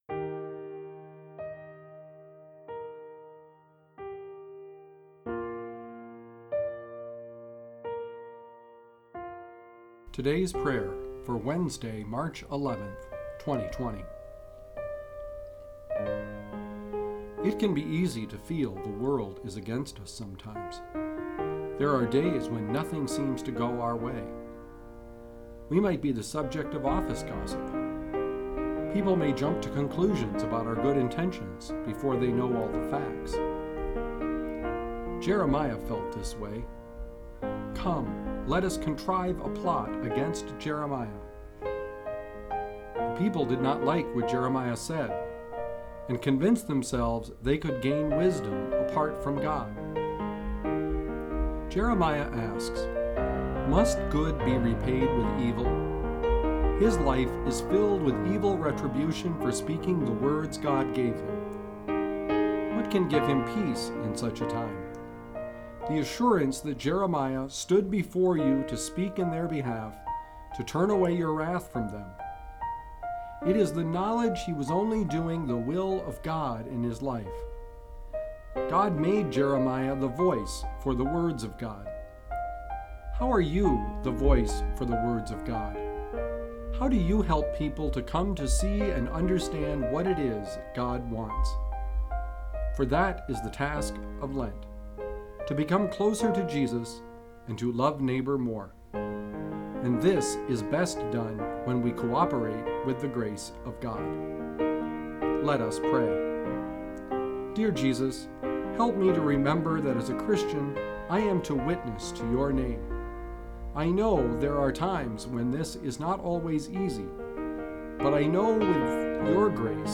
Today’s Prayer: March 11, 2020